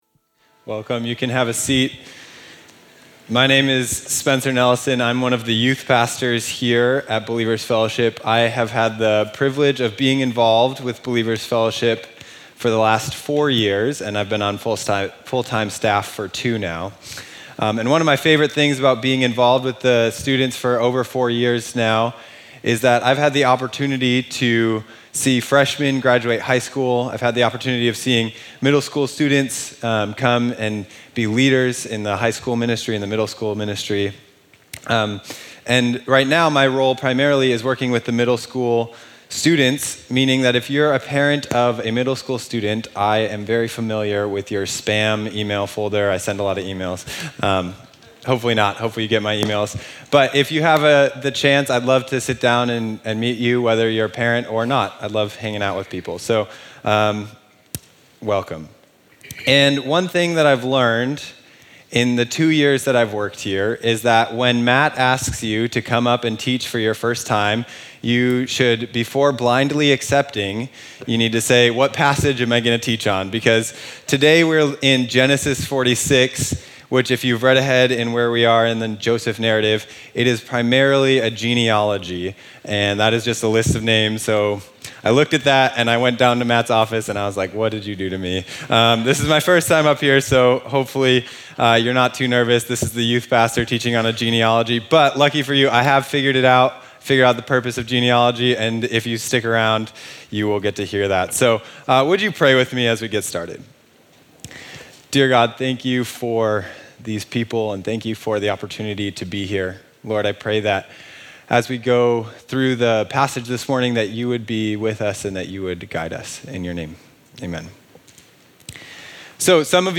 sermon
2018 at Believers Fellowship.